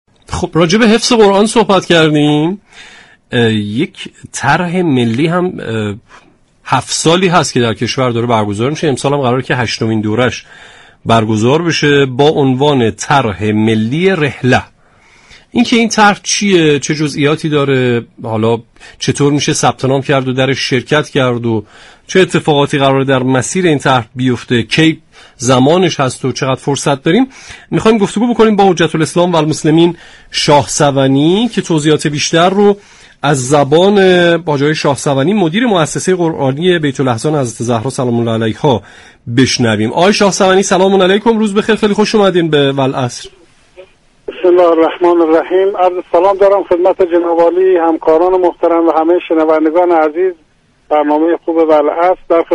در گفت و گو با برنامه والعصر رادیو قرآن از اجرای هشتمین دوره طرح ملی رحله خبر داد